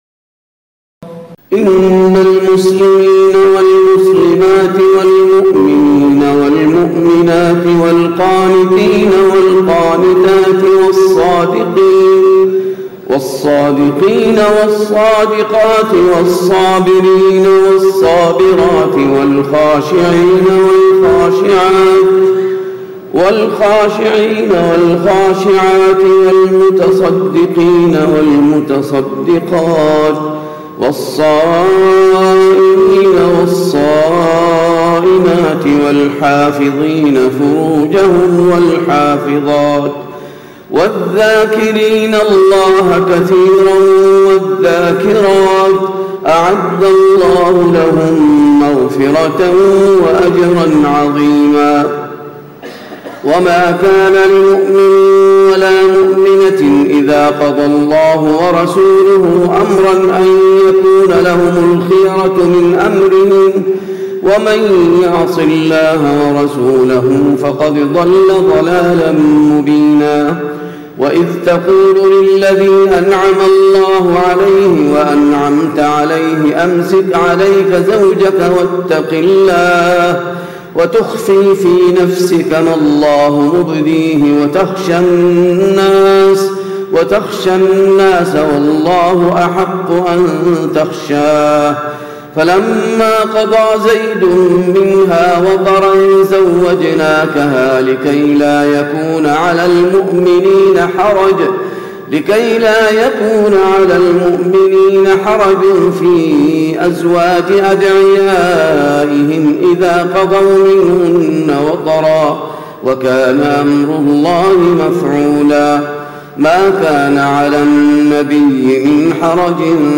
تراويح ليلة 21 رمضان 1436هـ من سورة الأحزاب (35-59) Taraweeh 21 st night Ramadan 1436H from Surah Al-Ahzaab > تراويح الحرم النبوي عام 1436 🕌 > التراويح - تلاوات الحرمين